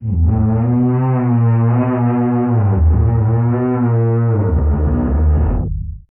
MOAN EL 07.wav